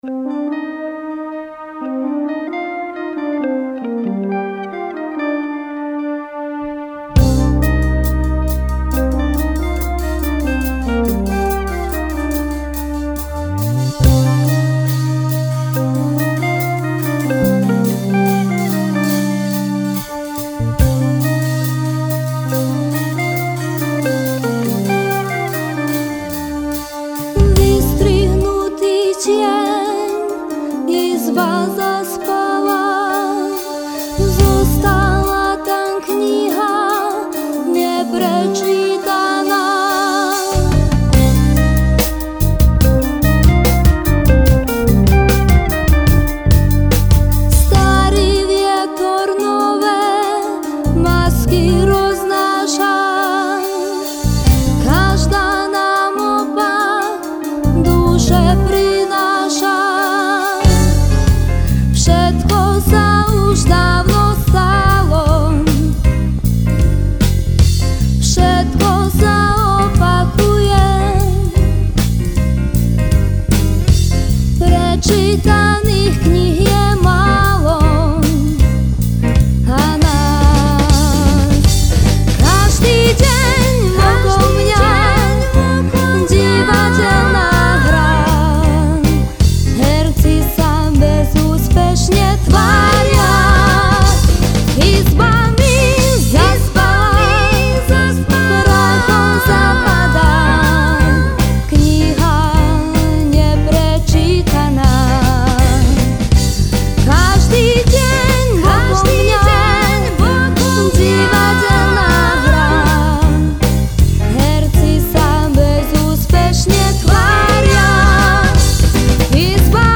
Divadelná hra